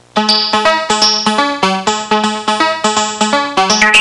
Disco Intro Sound Effect
Download a high-quality disco intro sound effect.
disco-intro.mp3